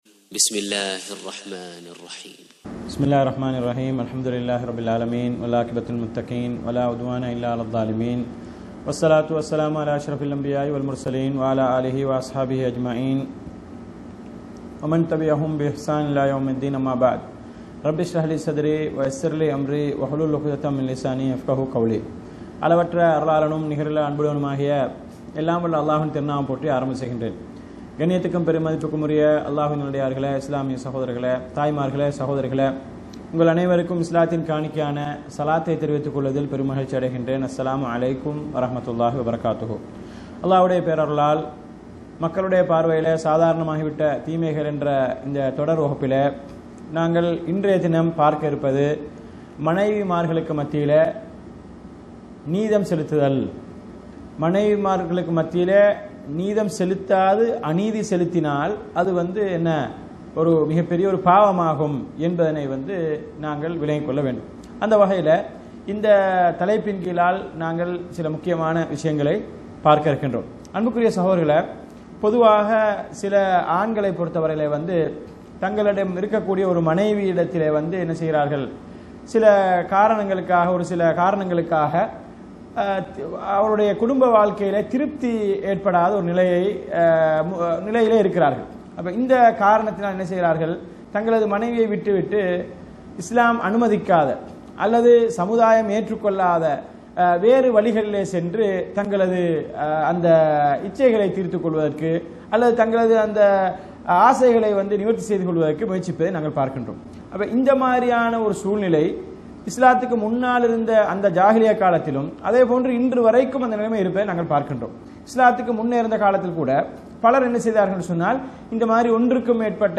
அஷ்ஷைக் முஹம்மத் ஸாலிஹ் அல்-முனஜ்ஜித் அவர்களால் தொகுக்கப்பட்ட ‘மக்களின் பார்வையில் சாதாரணமாகிவிட்ட தீமைகள்! எச்சரிக்கை!! என்ற நூலின் விளக்கவுரை!